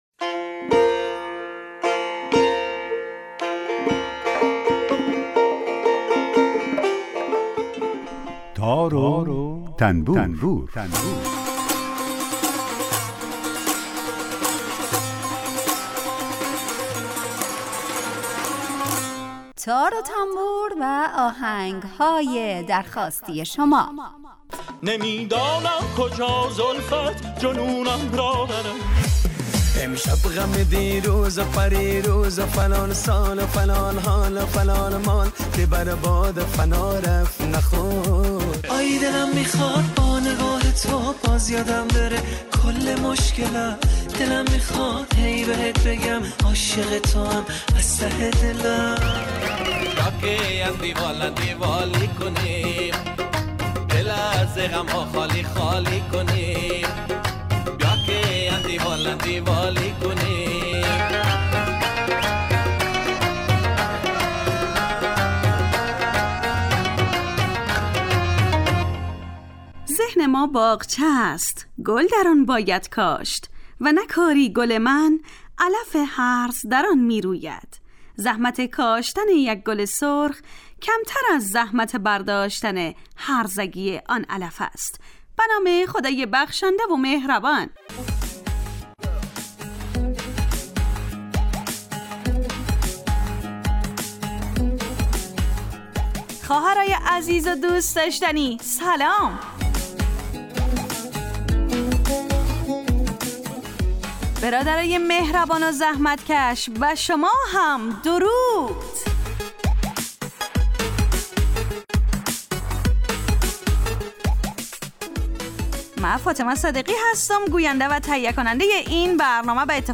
برنامه تار و تنبور هر روز از رادیو دری به مدت 40 دقیقه برنامه ای با آهنگ های درخواستی شنونده ها کار از گروه اجتماعی رادیو دری.
در این برنامه هر یه آیتم به نام در کوچه باغ موسیقی گنجانده شده که به معرفی مختصر ساز ها و آلات موسیقی می‌پردازیم و یک قطعه بی کلام درباره همون ساز هم نشر میکنیم